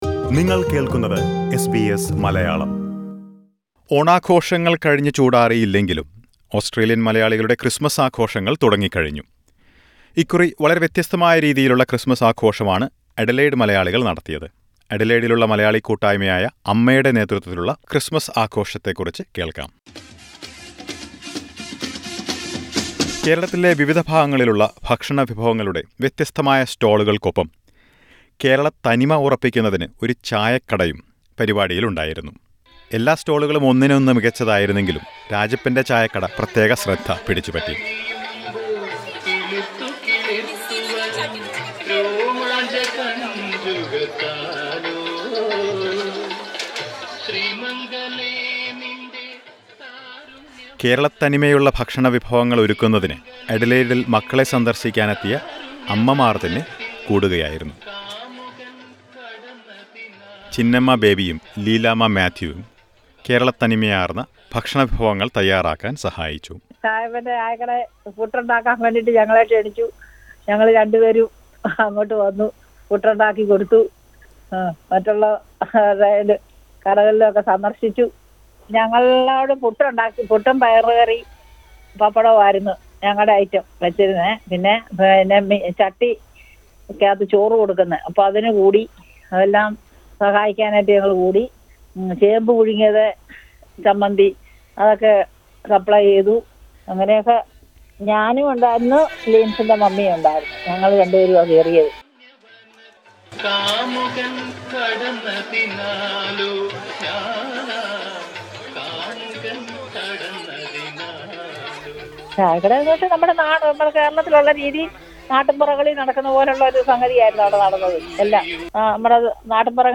മധ്യതിരുവിതാംകൂർ സ്പെഷ്യൽ വിഭവങ്ങളുമായി അച്ചായൻസ് കോർണർ Source: Supplied പാരിപാടിയിൽ പങ്കെടുത്തവരുടെയും സംഘാടകരുടെയും അഭിപ്രായങ്ങൾ കേൾക്കാം ഇവിടെ.